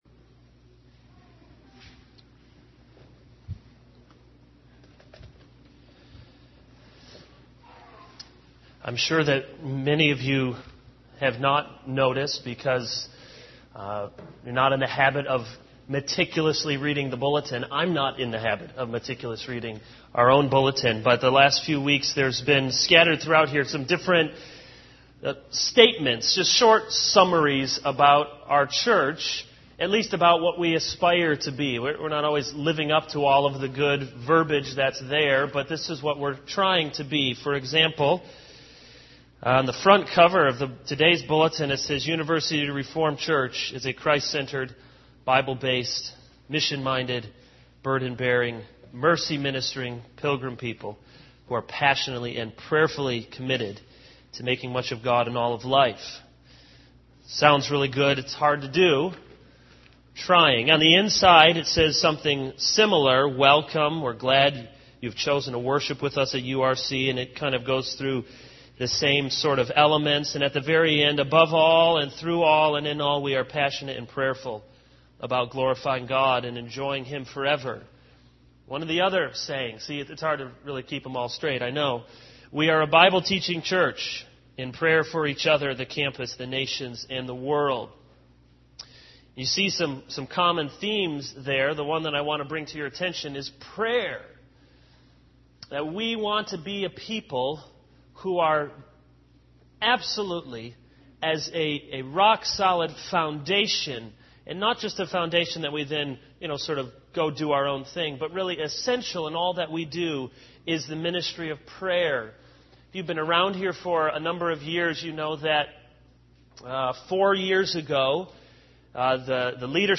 This is a sermon on Exodus 32:1-14.